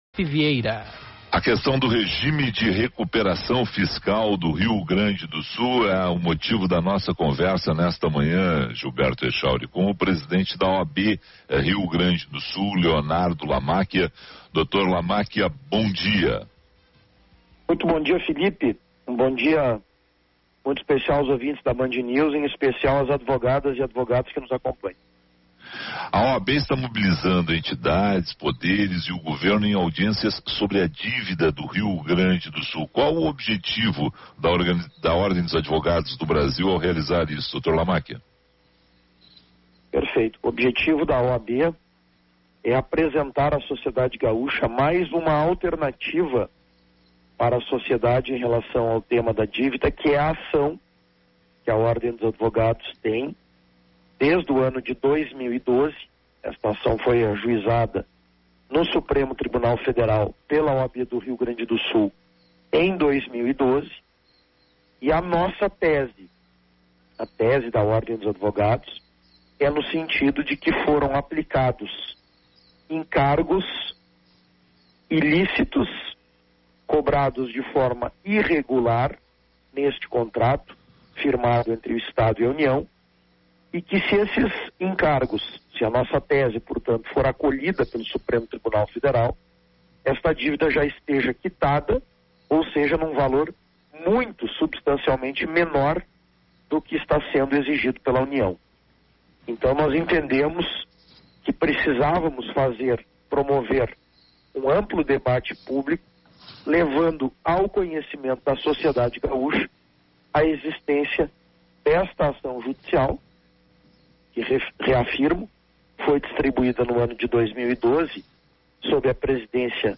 Entrevista com o presidente da OAB/RS, Leonardo Lamachia